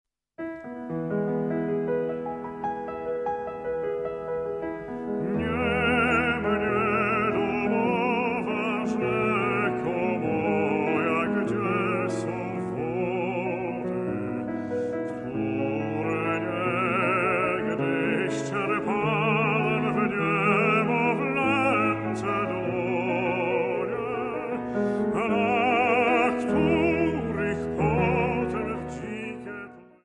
Fortepian